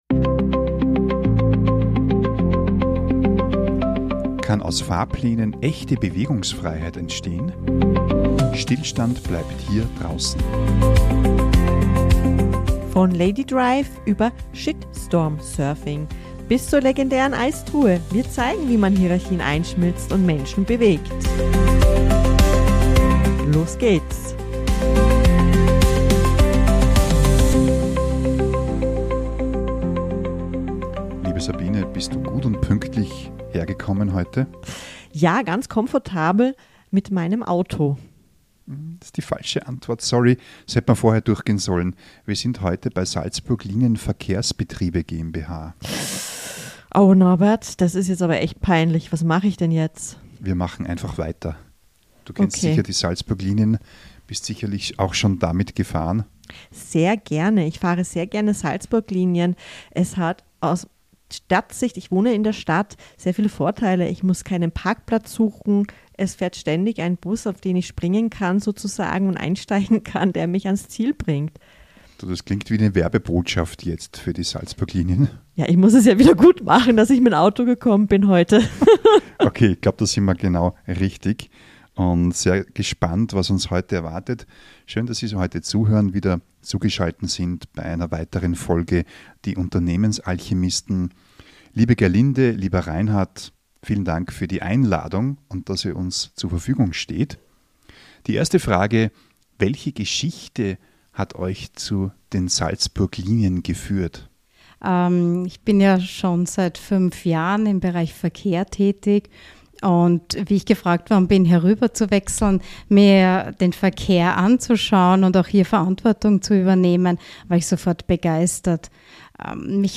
Es geht um den Wandel von hierarchischen Strukturen hin zu einer modernen, wertschätzenden Unternehmenskultur, um Beteiligung, Vertrauen und das gemeinsame Feiern von Erfolgen. Einblicke gibt es außerdem in Themen wie Führung auf Augenhöhe, Mitarbeiterbindung, Frauenförderung im Verkehrswesen und den Umgang mit Feedback – vom Lob bis zum Shitstorm. Ein inspirierendes Gespräch über Verantwortung, Menschlichkeit und die Vision, wie nachhaltige Mobilität in Salzburg gelingen kann.